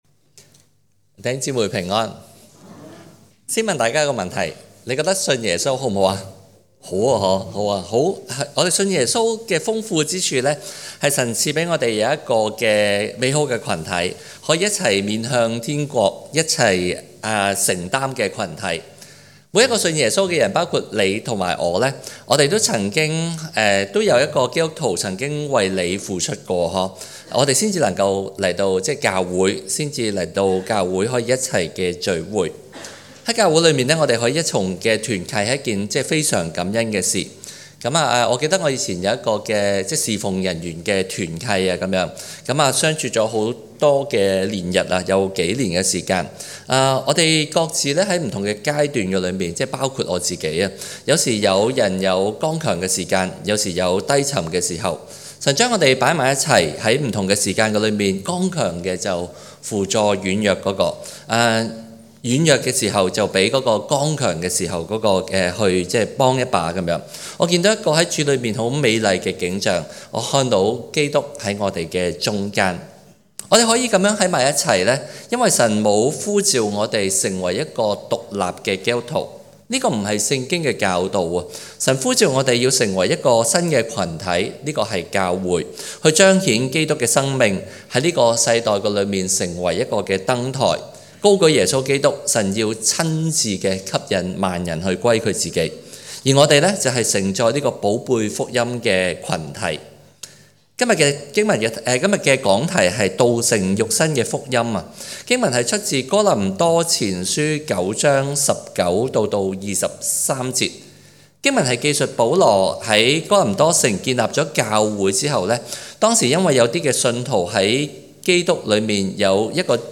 講道重溫
講道類別 : 主日崇拜 經文章節 : 哥林多前書 9 : 19 - 23)